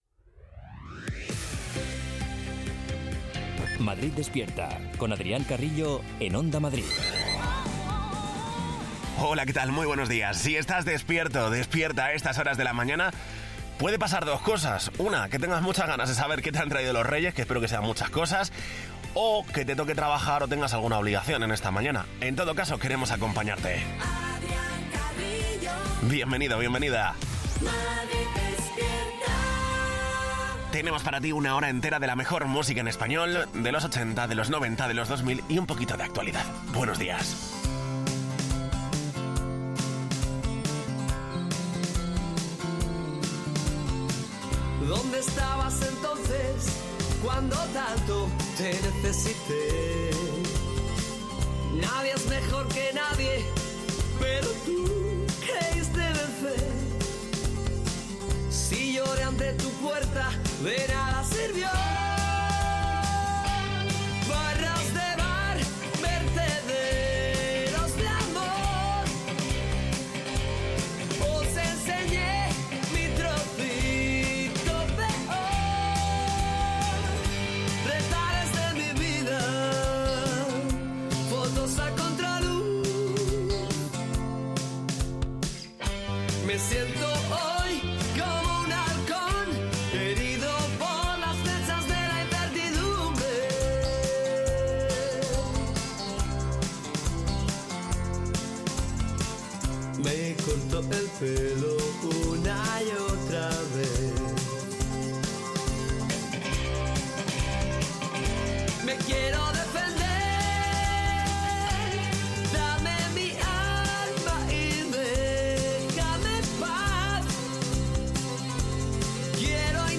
Morning show para despertar a los madrileños con la mejor música y la información útil para afrontar el día.